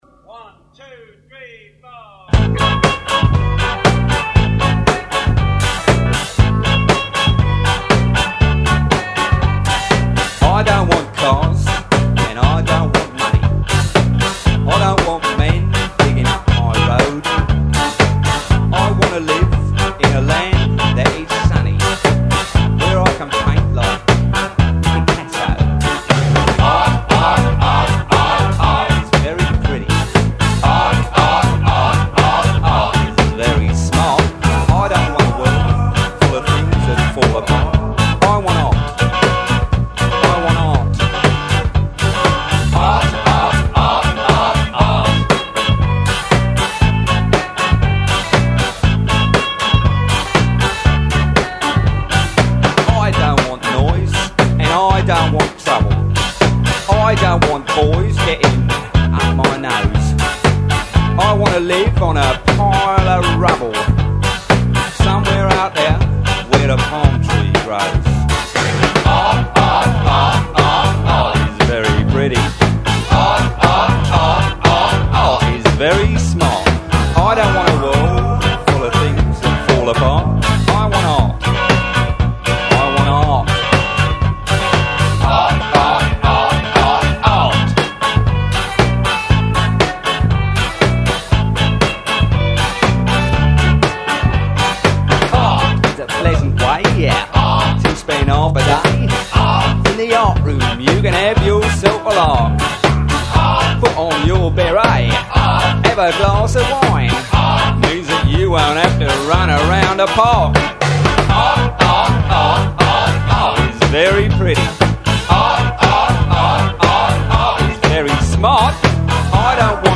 on vocal.